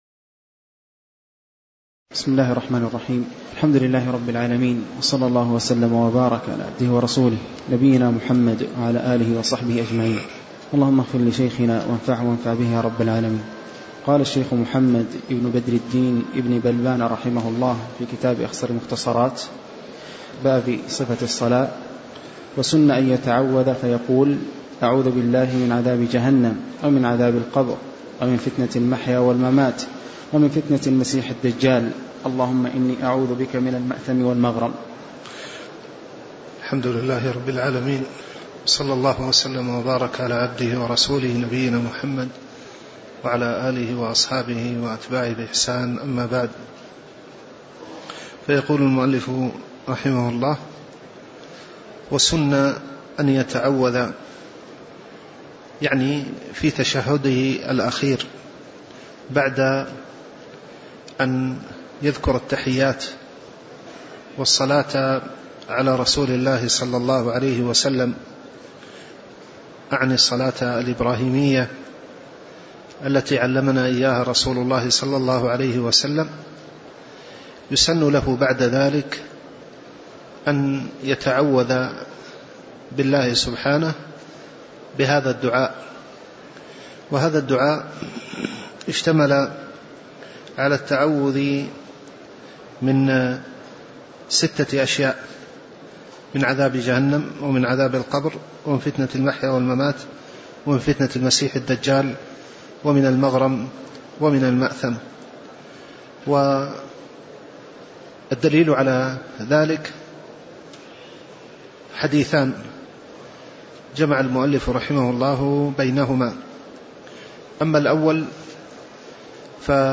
تاريخ النشر ٥ جمادى الآخرة ١٤٣٩ هـ المكان: المسجد النبوي الشيخ